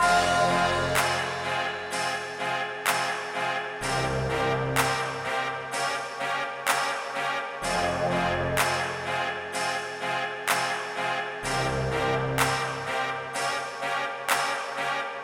808黑手党类型的黄铜，带镲片铃铛拍子的KHB
描述：增加了额外的铃铛和镲片与拍子嗨帽
Tag: 126 bpm Trap Loops Brass Loops 2.56 MB wav Key : Unknown